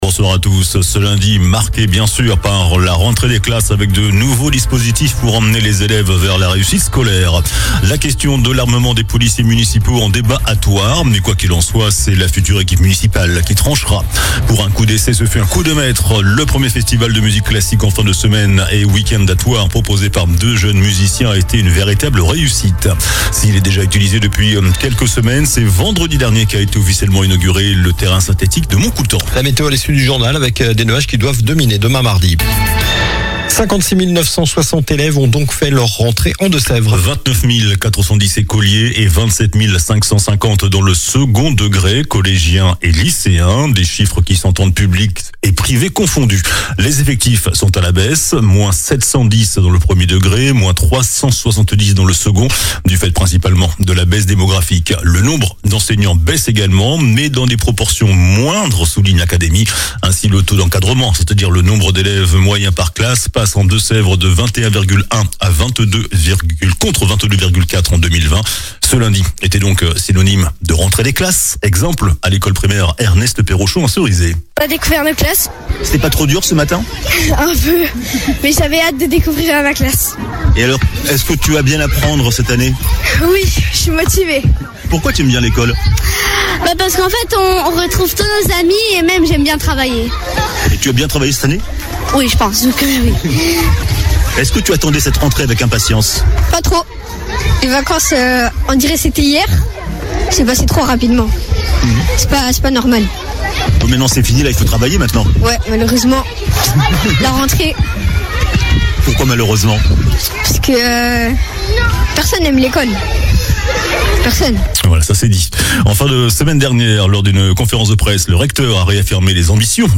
JOURNAL DU LUNDI 01 SEPTEMBRE ( SOIR )